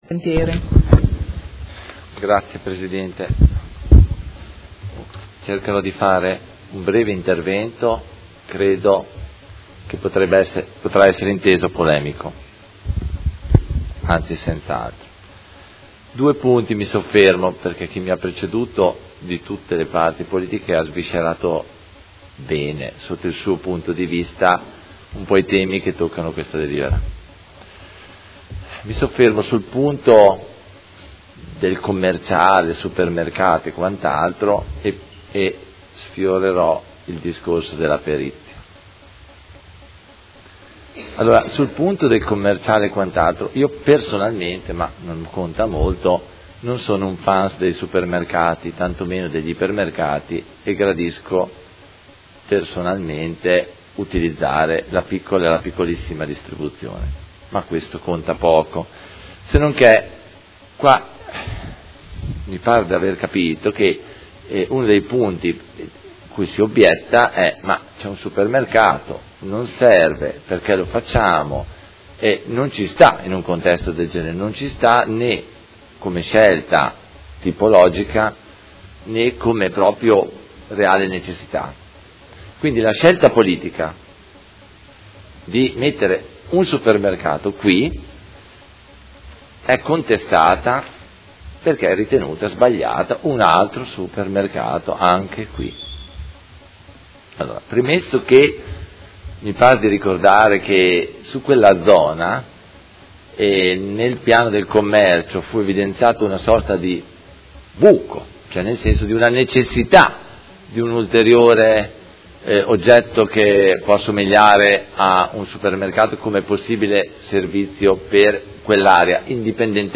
Antonio Carpentieri — Sito Audio Consiglio Comunale
Seduta del 12/07/2018. Dibattito su proposta di deliberazione: Riqualificazione, recupero e rigenerazione urbana del comparto “ex sede AMCM” - Valutazione degli esiti della procedura competitiva con negoziazione in relazione ai contenuti del Documento di Indirizzo.